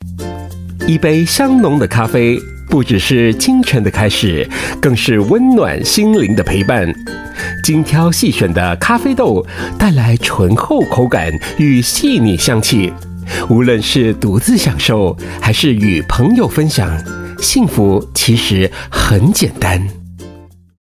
Voice Samples: F&B
male